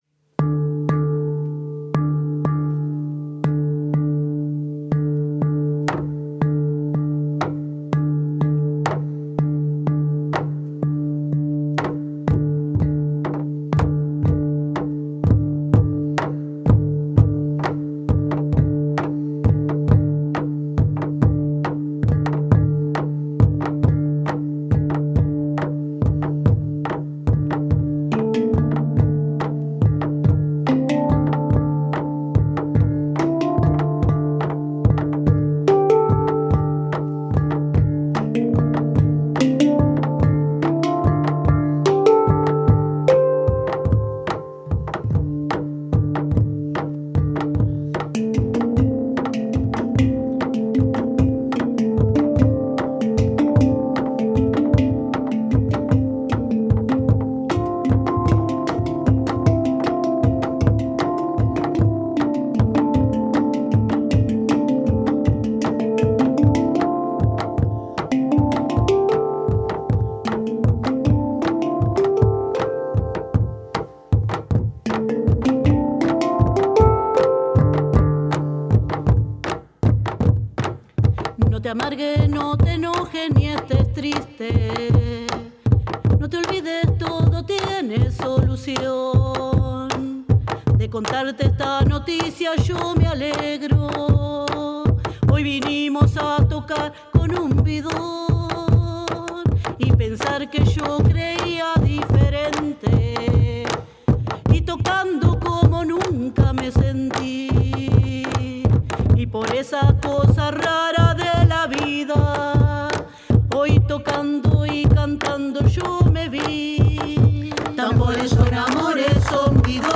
Desde la Gerencia de Formación Cultural, con el objetivo de potenciar las instancias formativas y en coordinación con la Usina Cultural de Parque del Plata, se grabaron productos musicales de los participantes de los talleres que se llevan a cabo en el departamento.
5._percusion_alternativa_chacarera.mp3